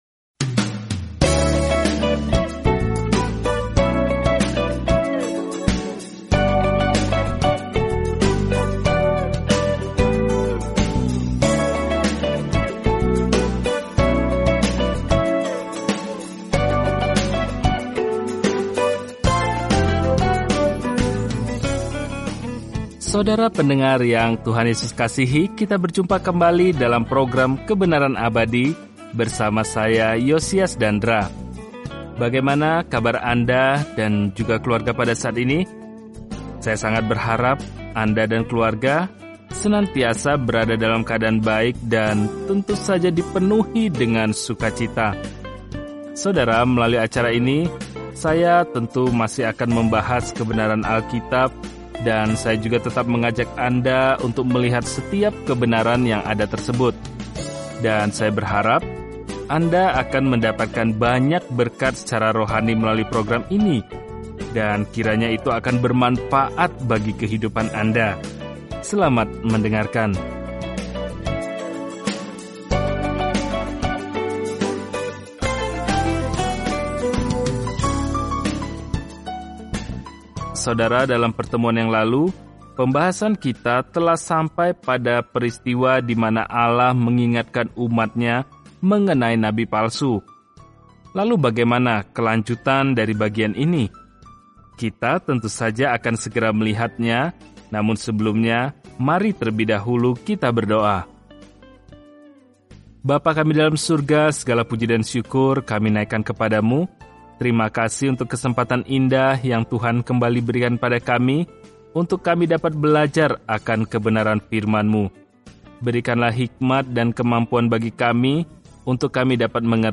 Firman Tuhan, Alkitab Ulangan 13:6-18 Ulangan 14:1-29 Hari 8 Mulai Rencana ini Hari 10 Tentang Rencana ini Ulangan merangkum hukum baik Allah dan mengajarkan bahwa ketaatan adalah tanggapan kita terhadap kasih-Nya. Telusuri Ulangan setiap hari sambil mendengarkan pelajaran audio dan membaca ayat-ayat tertentu dari firman Tuhan.